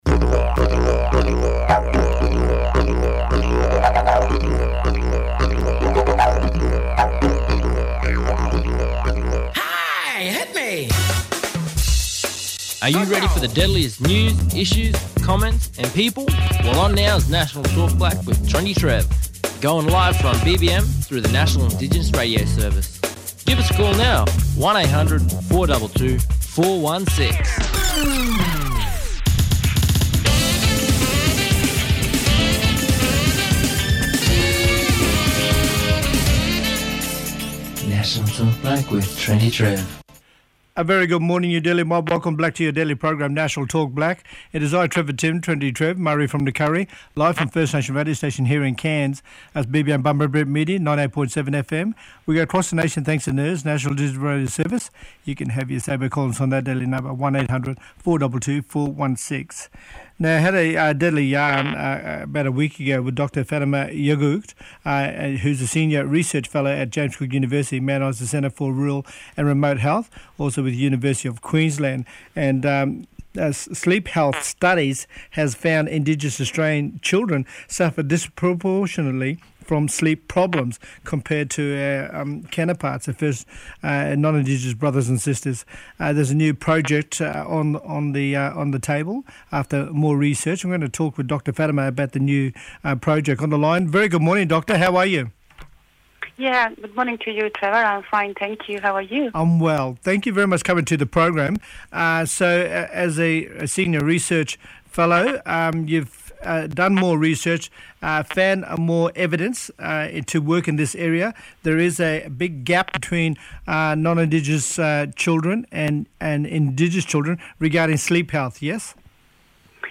This interview, she talks about the recent project that aims to improve sleep health of Indigenous children.